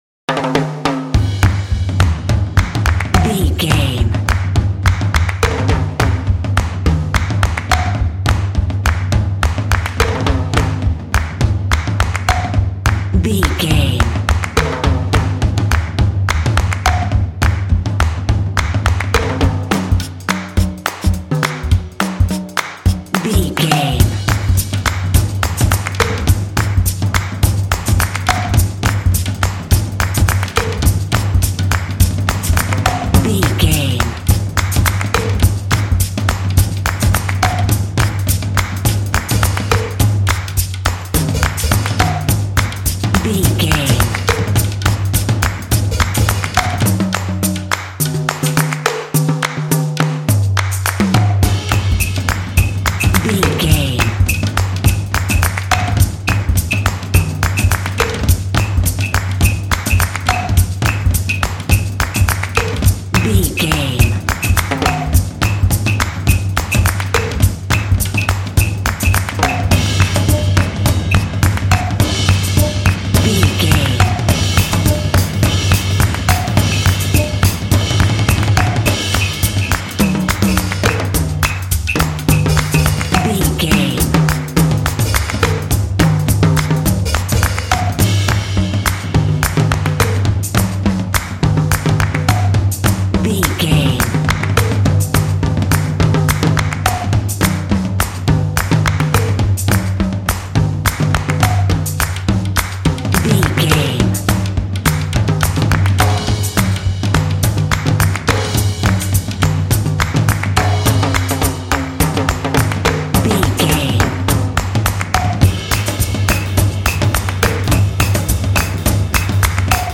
Uplifting
Aeolian/Minor
driving
energetic
lively
cheerful/happy
percussion